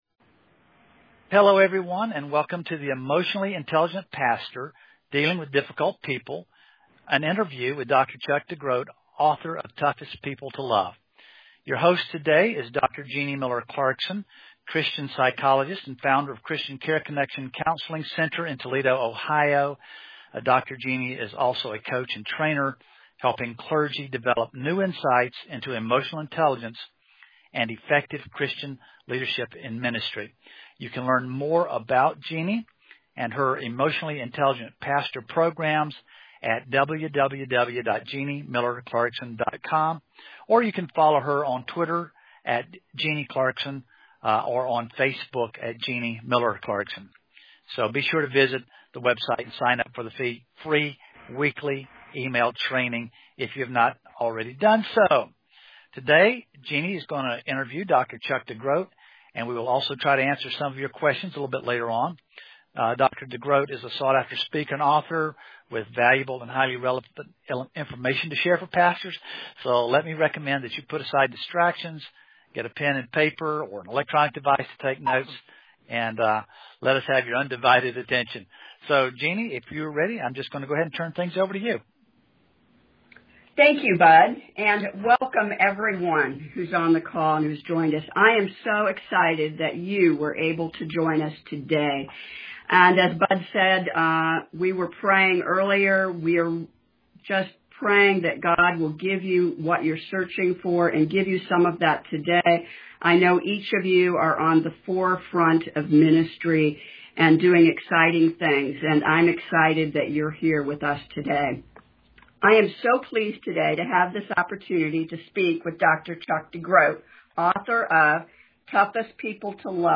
Note: Around the 14 minute mark there is a moment of poor recording quality. We apologize but it only lasts for a few seconds! 33:53 Dealing with Difficult People